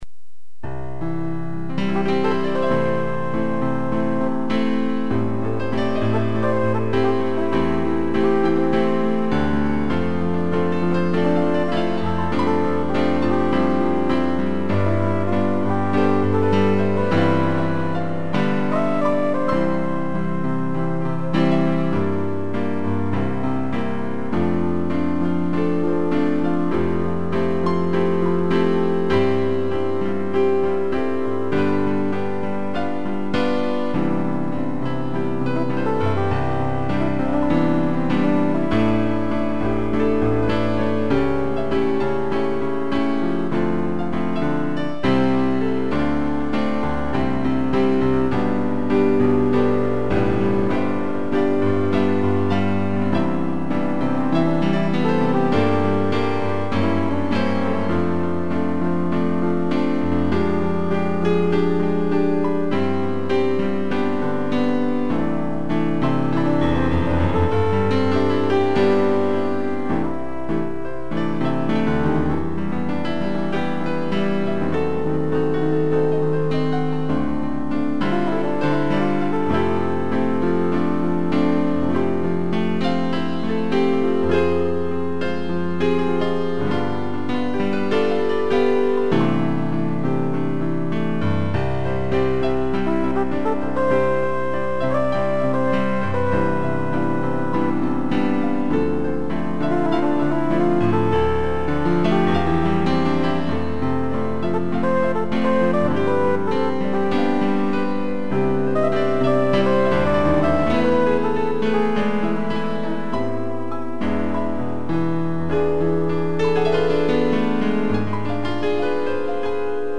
2 pianos e flugel horn
(instrumental)